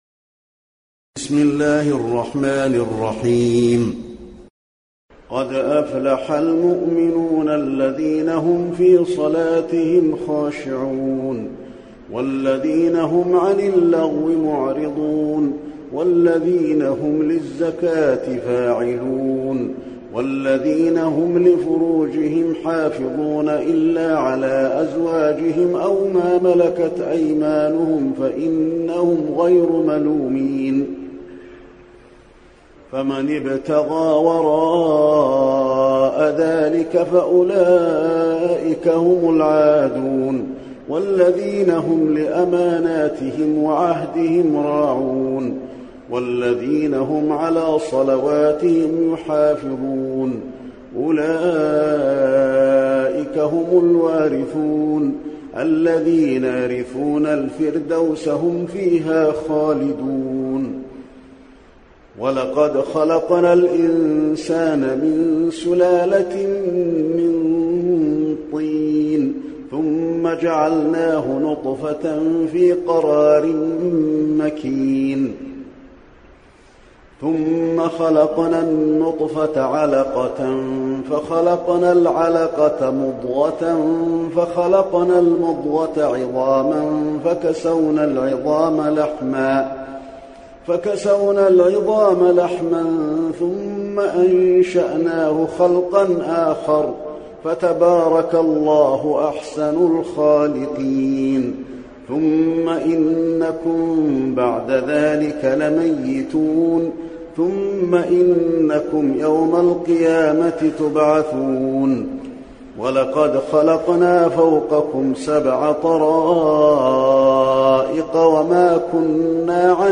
المكان: المسجد النبوي المؤمنون